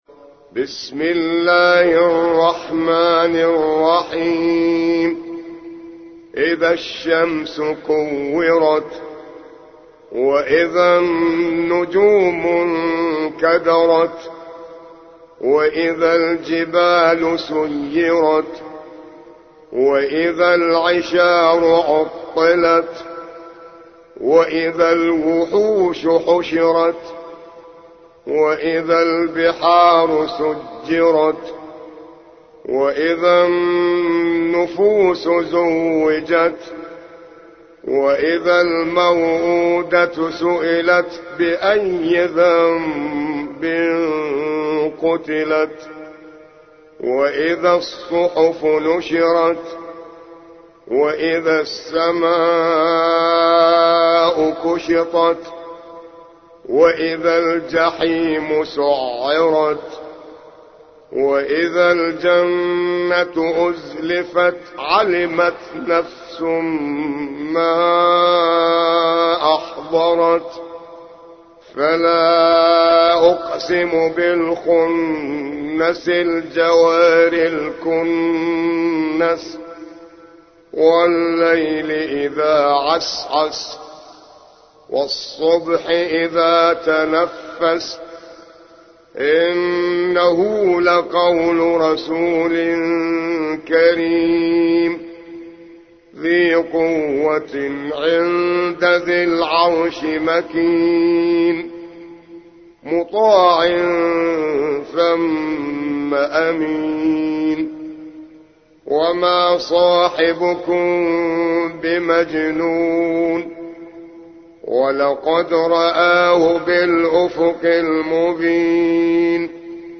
81. سورة التكوير / القارئ